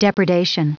Prononciation du mot depredation en anglais (fichier audio)
Prononciation du mot : depredation